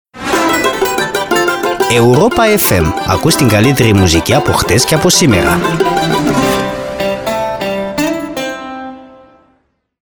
De Ziua Națională a Greciei, am provocat vorbitorii nativi de limbă greacă să ne ofere un curs rapid.
Acestea sunt însoțite și de jingleuri Europa FM speciale, pe care vă ajutăm să le înțelegeți